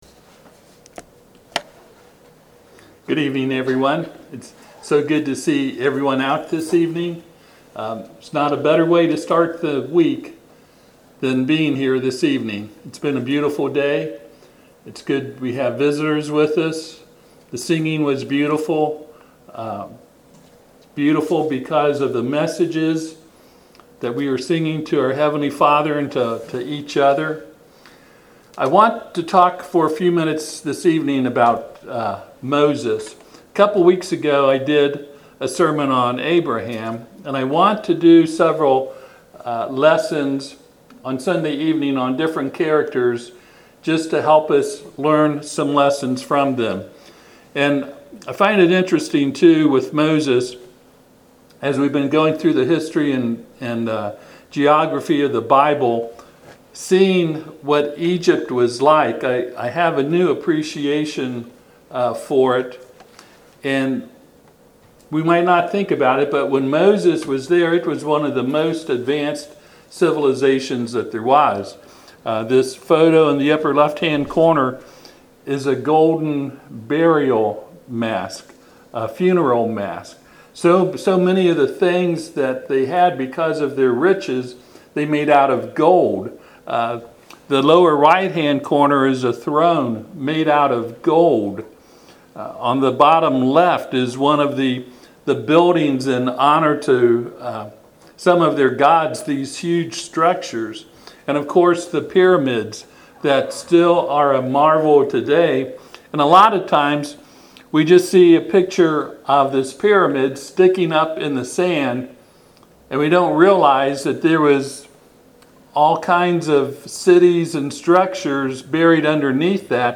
Passage: Hebrews 11:23-29 Service Type: Sunday PM